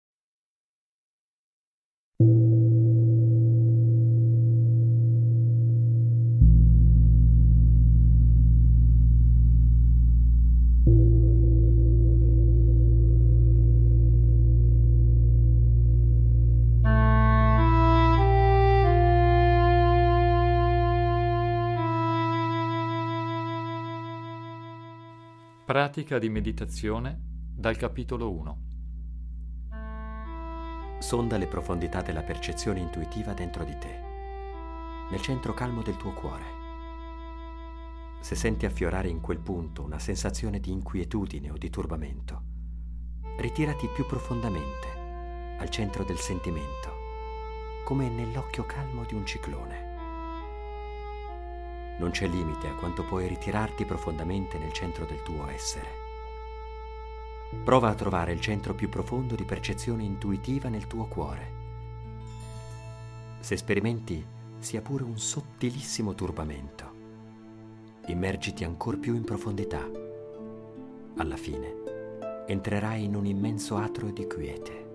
17 meditazioni guidate
La musica contenuta nel CD scaricabile è particolarmente indicata per la meditazione, lo yoga, il rilassamento e la ricerca spirituale.